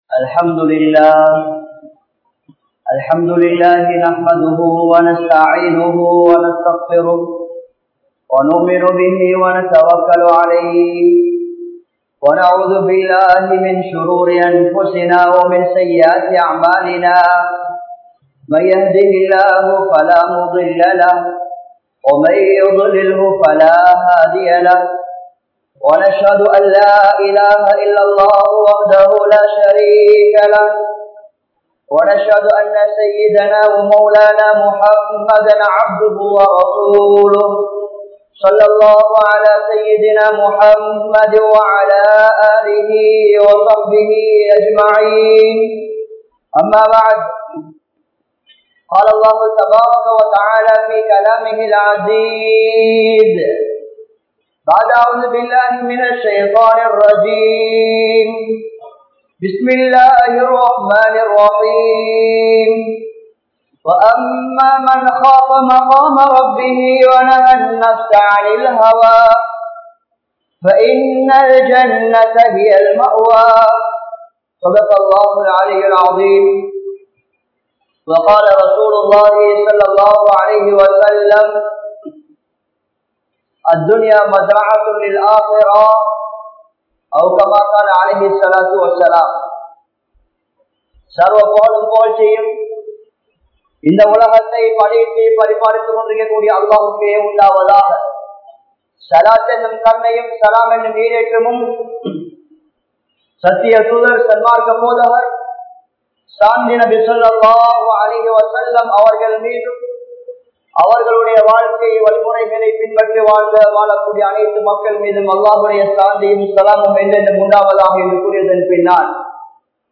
Nabi(SAW)Avarhalin Pirappum Kavalaium (நபி(ஸல்) அவர்களின் பிறப்பும் கவலையும்) | Audio Bayans | All Ceylon Muslim Youth Community | Addalaichenai
Grand Jumua Masjidh